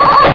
bark2.ogg